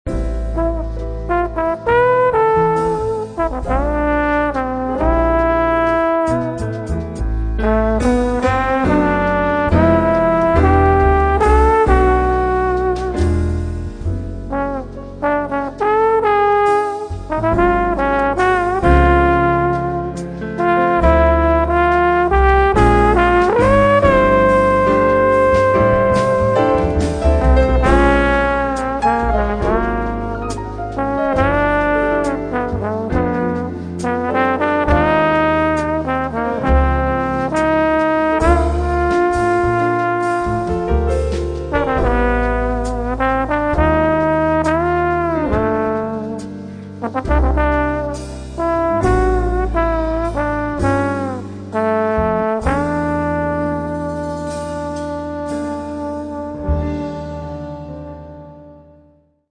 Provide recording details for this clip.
Recorded at NRK Studio 20, October 19th, 20th 1998.